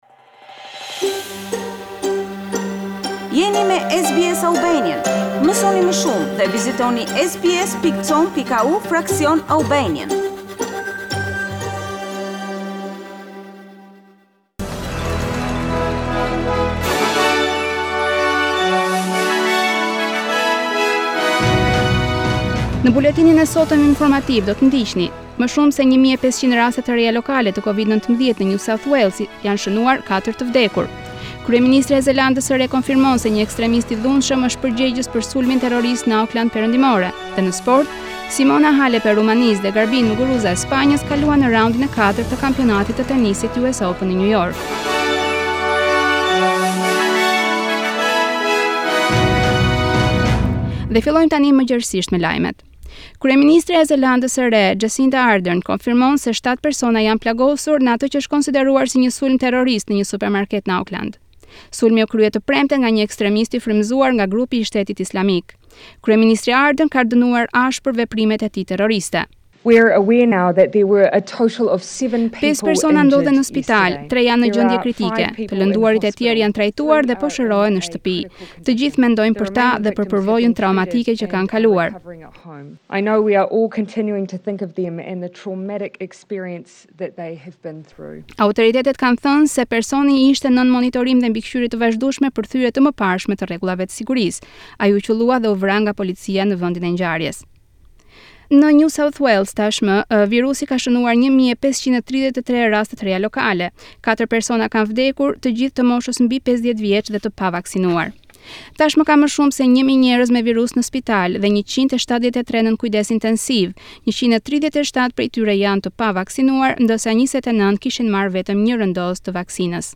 SBS News Bulletin in Albanian- 04 September 2021